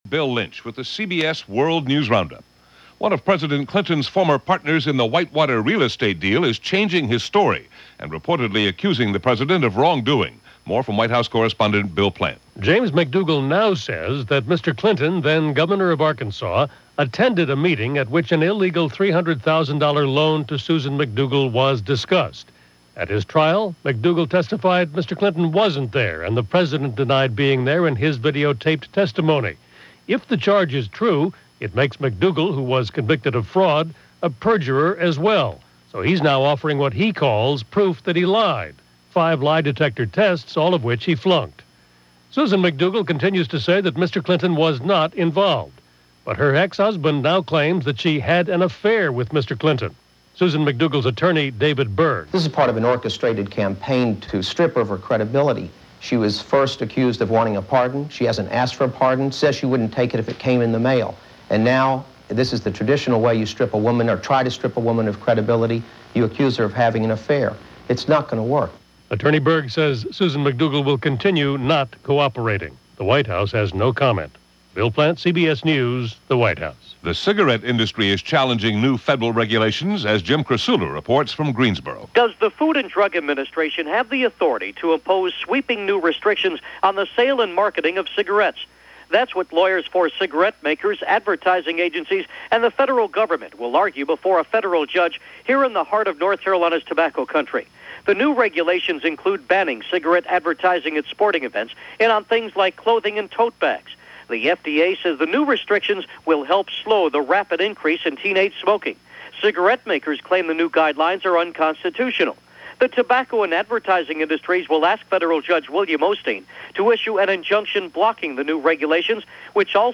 And that’s only a small slice of what went on, this February 10, 1997 as reported by The CBS World News Roundup.